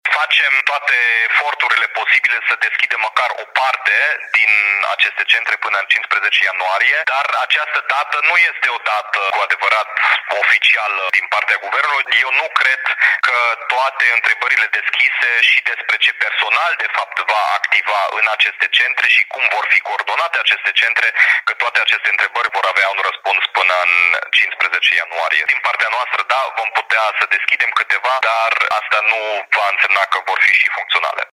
Primarul Dominic Fritz vorbește despre lipsa unor reglementări clare în ceea ce privește atât dotarea centrelor de vaccinare, cât și personalul care le va deservi.